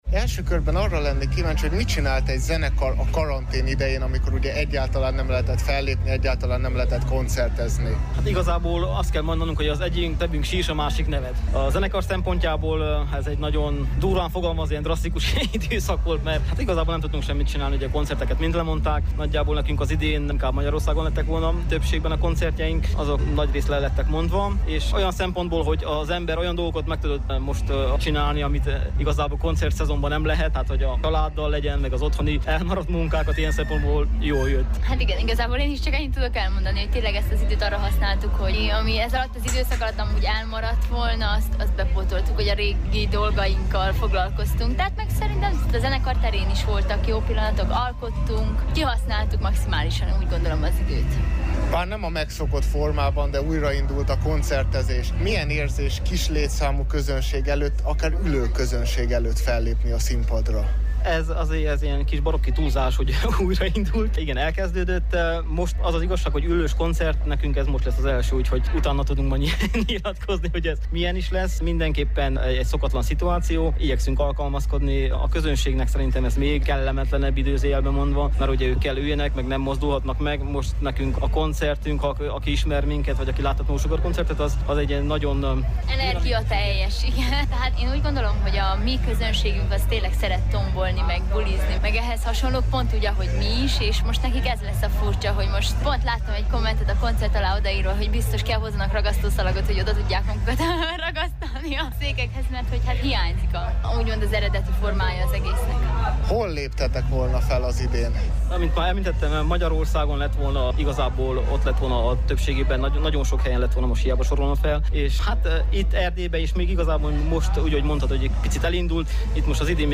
A Double Rise csíkszeredai állomásán fellépett a No Sugar zenekar is. Elmondásuk szerint a karantén ideje alatt, amikor nem lehetett koncertezni, az egyik szemük sírt, a másik pedig nevetett, hiszen egyrészt nem tudtak fellépni, de be tudtak pótolni bizonyos dolgokat, amikre eddig, a koncertek miatt, nem volt lehetőségük.